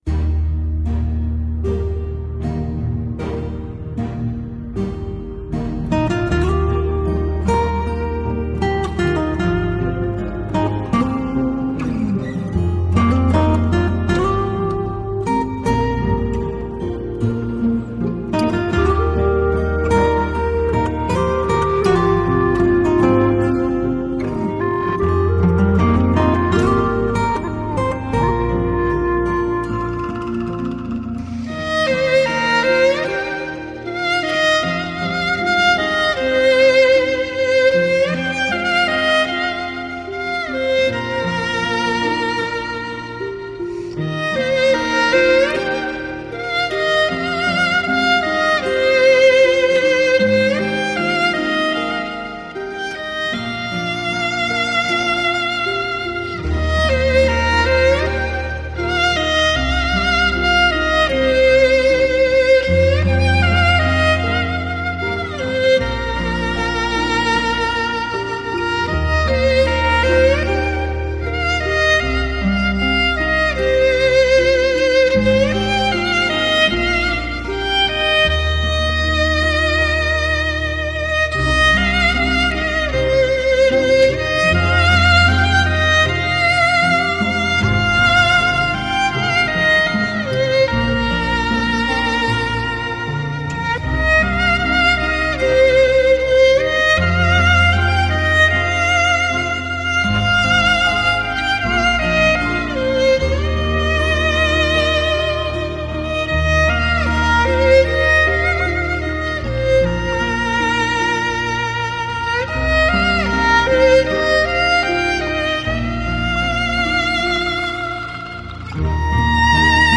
草原的音乐热情，真挚。
Surround 7.1德国技术STS三维高临场音效，美国最新技术高清录音紫水晶CD。